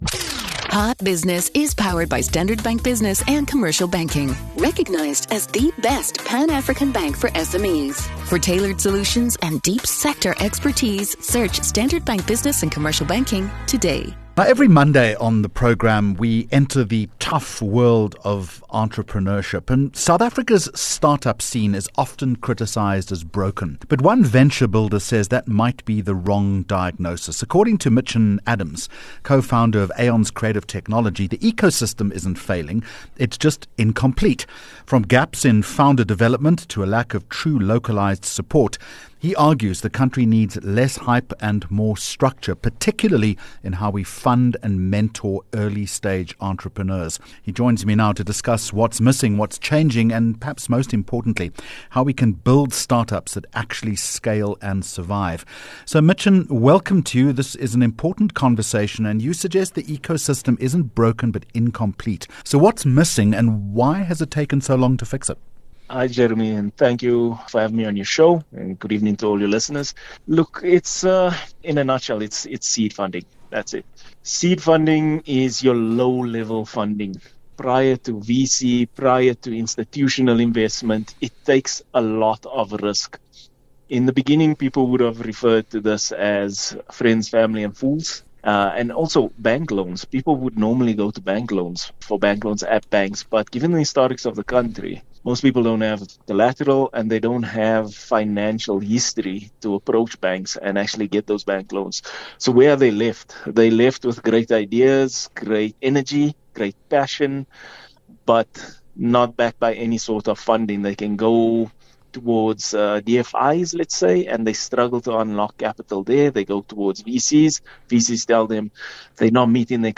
21 Jul Hot Business Interview